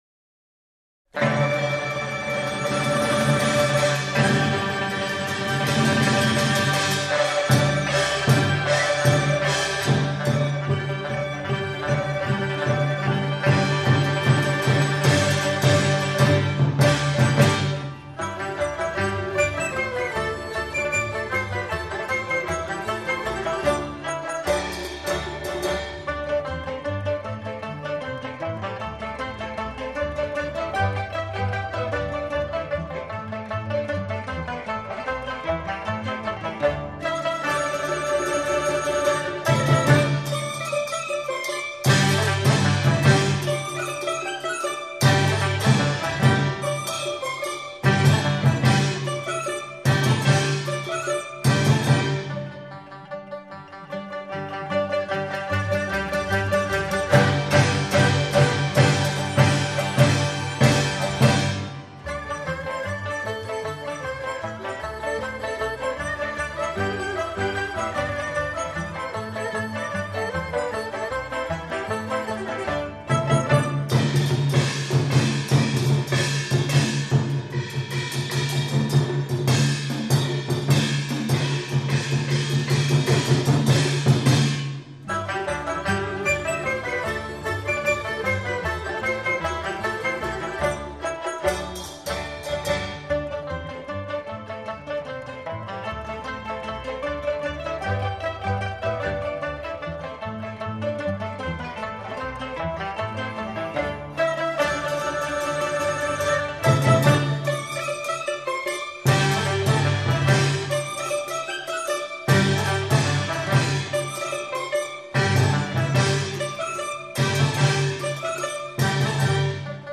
這張專輯收錄的都是中國南方的小調。
均為最正統的國樂演出
(樂團合奏)